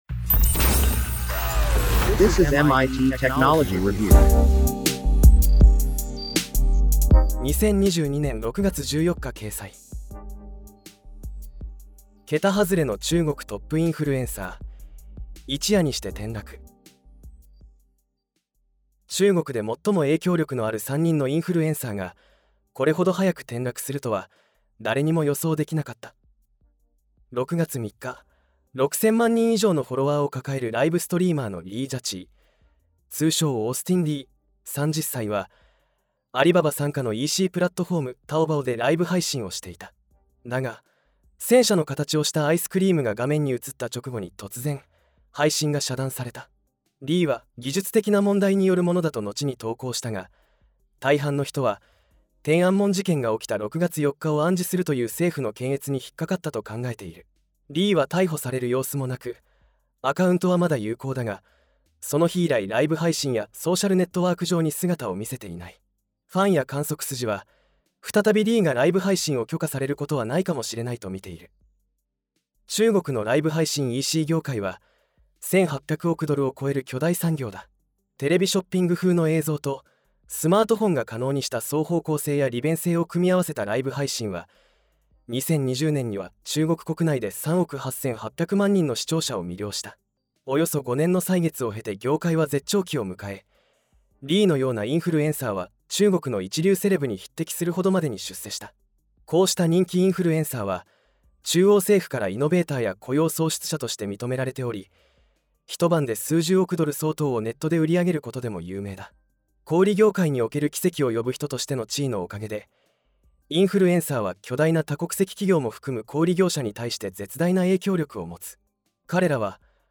なお、本コンテンツは音声合成技術で作成しているため、一部お聞き苦しい点があります。